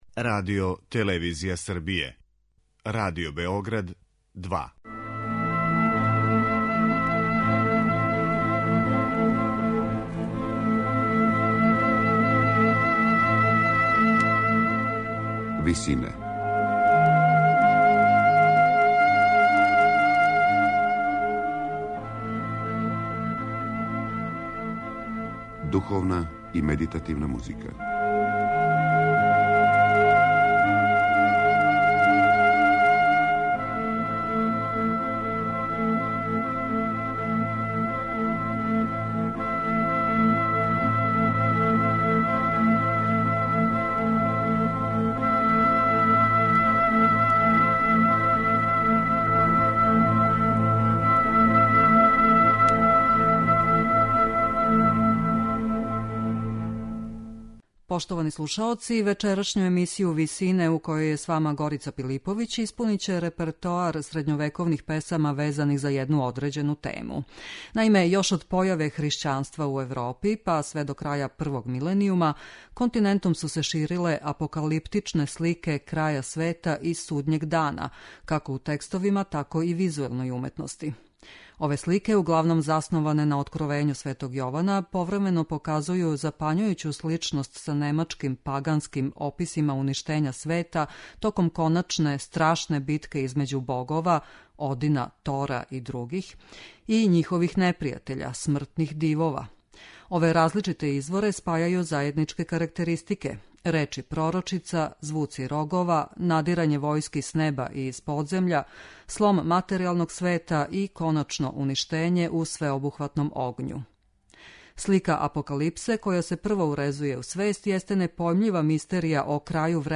Aнсамбл Секвенција изводи средњовековне песме везане за слике апокалипсе
Извођачи су чланови чувеног ансамбла Секвенција.